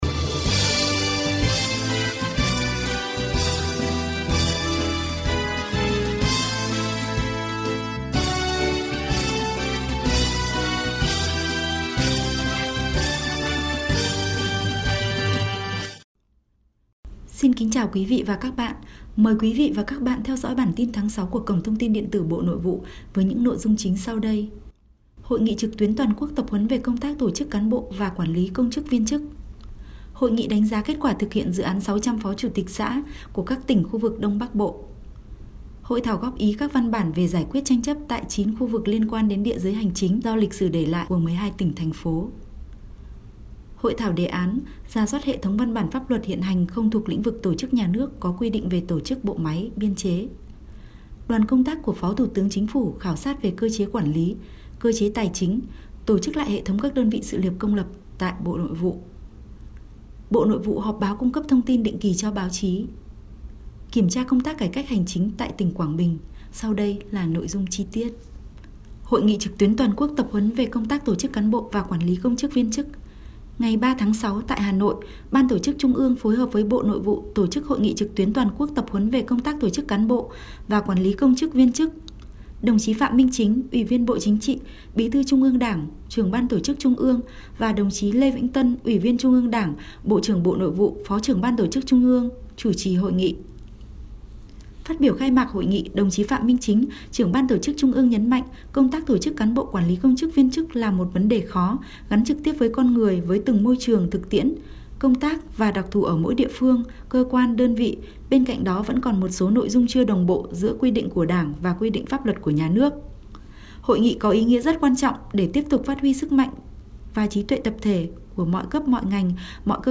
Bản tin audio ngành Nội vụ số tháng 05 năm 2018